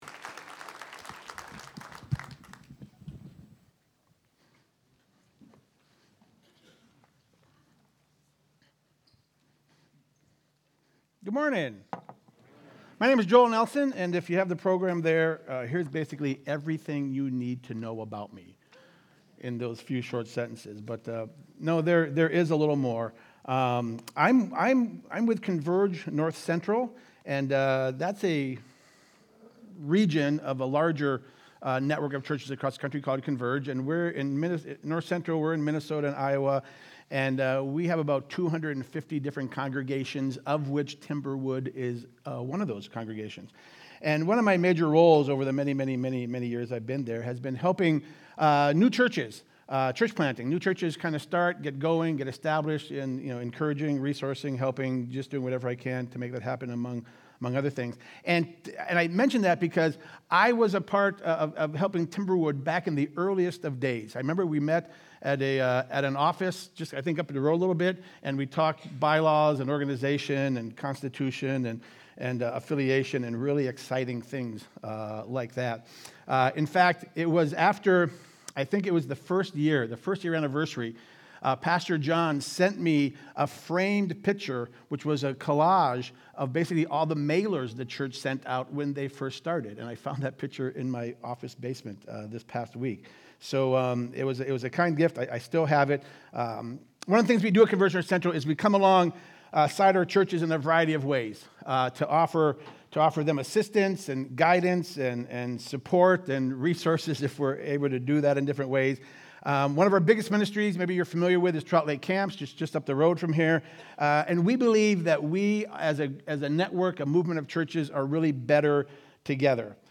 Sunday Sermon: 8-10-25